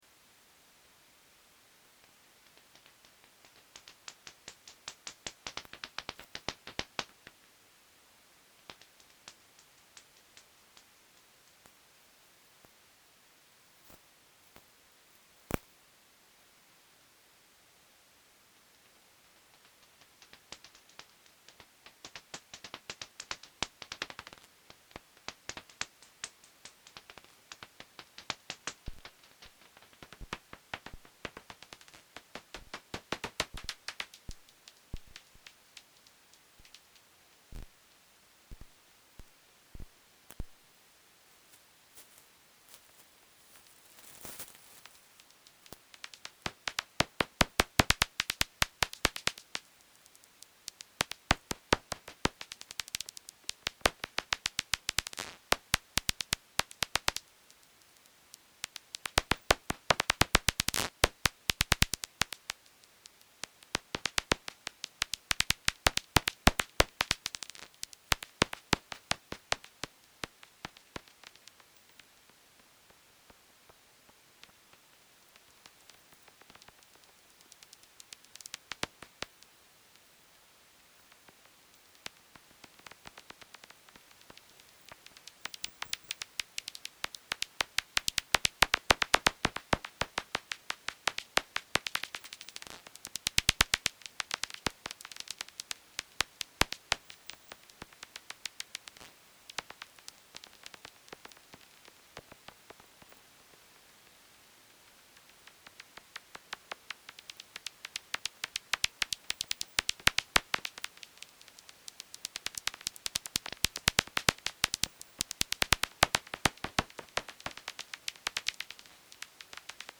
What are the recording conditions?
Willem Buytewechstraat, july 09 For the recording I used a directional microphone, a contact microphone, an ultrasonic detector (bats) and a binaural microphone.